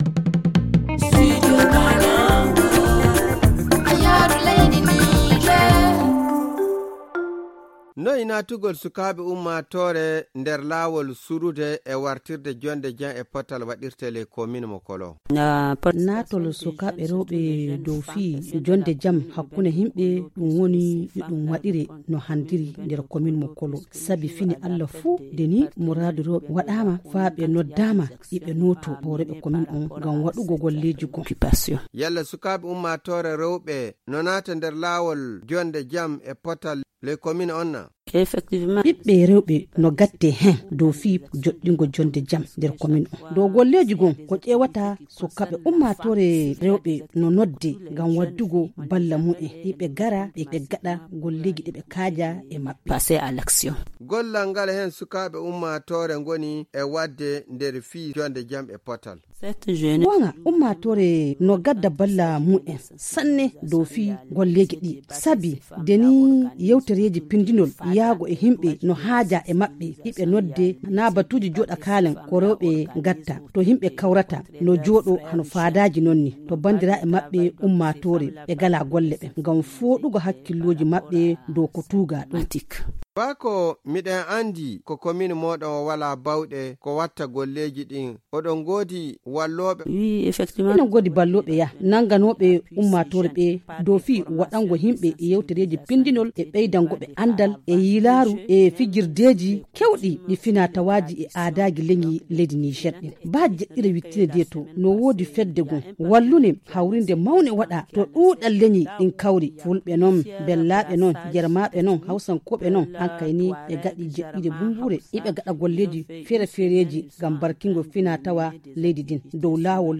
Les autorités, les partenaires, les jeunes, et les femmes sont tous à pied d’œuvre pour prévenir et consolider la paix dans le département. Salamatou Souley, maire de kollo donne quelques éléments de réponse
Le magazine en fulfuldé